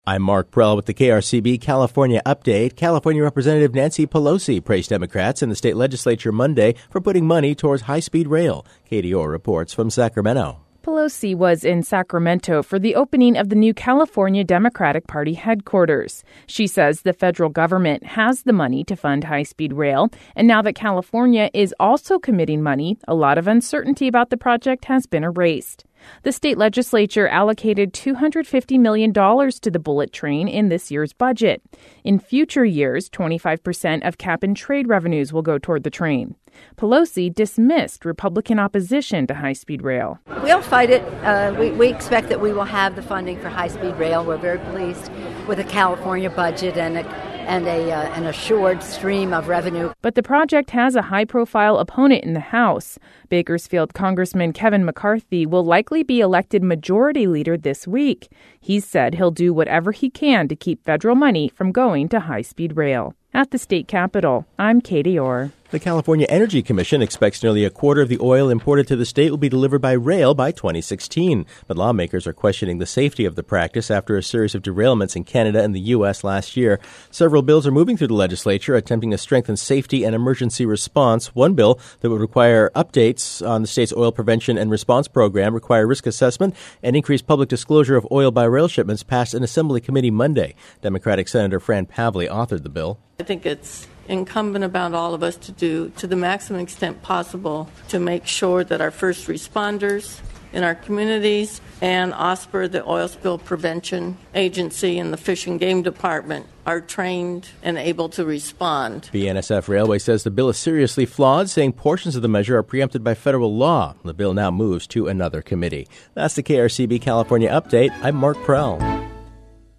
Congresswoman Nancy Pelosi speaks at the opening of the new headquarters for the Californian Democratic Party in Sacramento on Monday, June 6, 2014.